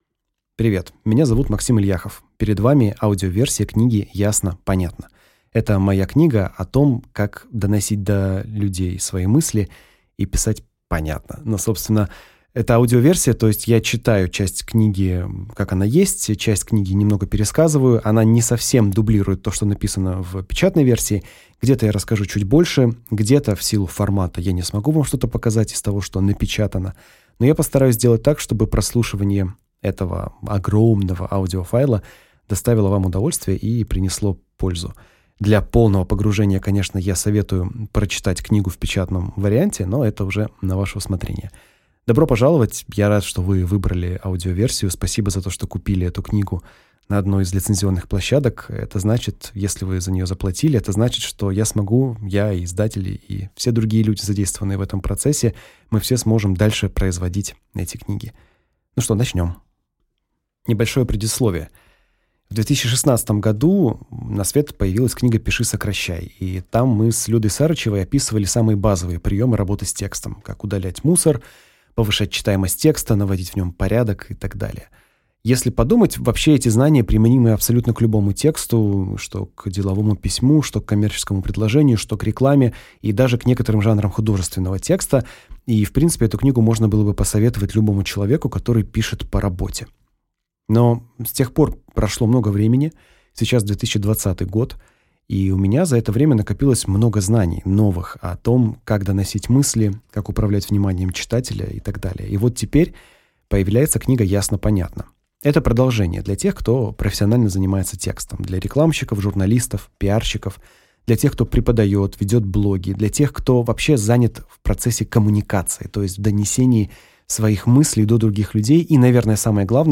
Аудиокнига Ясно, понятно. Как доносить мысли и убеждать людей с помощью слов | Библиотека аудиокниг